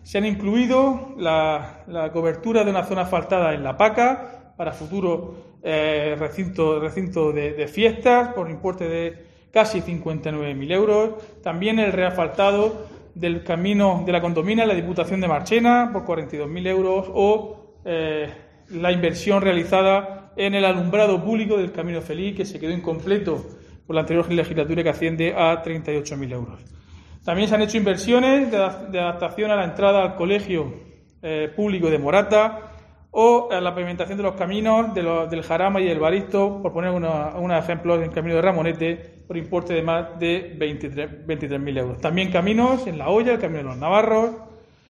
Diego José Mateos, alcalde de Lorca sobre pedanías